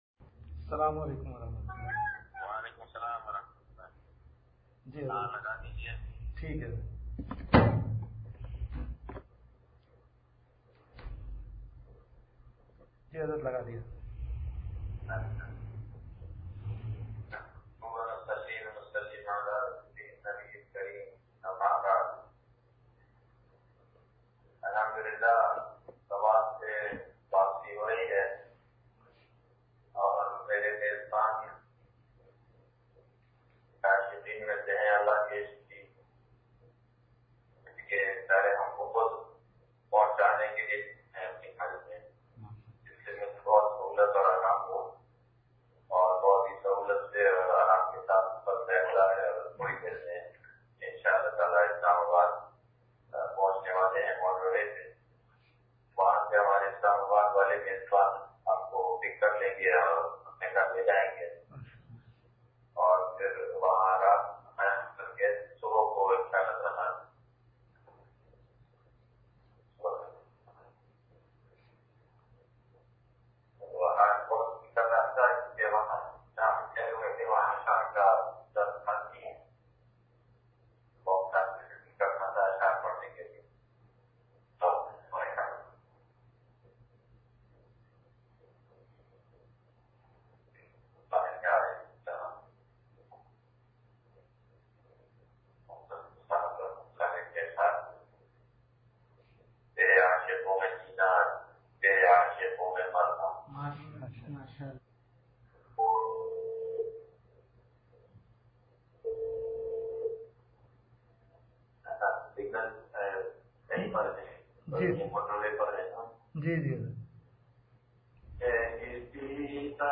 حضرت والا دامت بر کاتہم کا بیان سوات سے – ترے عاشقوں میں جینا ترے عاشقوں میں مرنا – مواھب ربانیہ – نشر الطیب فی ذکر النبی الحبیب صلی اللہ علیہ وسلم